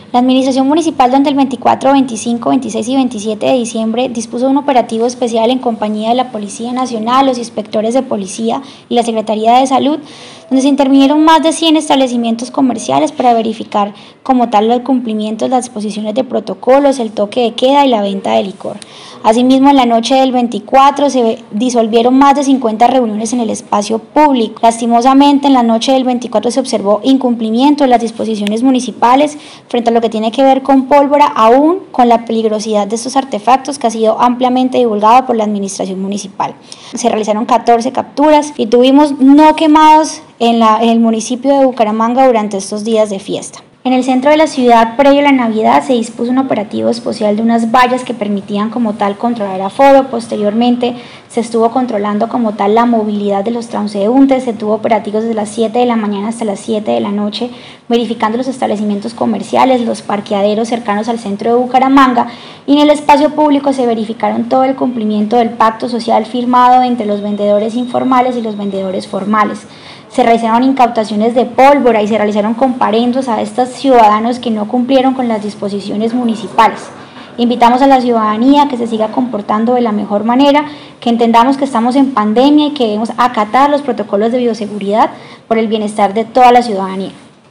Descargar audio: Melissa Franco, subsecretaria del Interior de Bucaramanga.
Melissa-Franco-subsecretaria-del-Interior.wav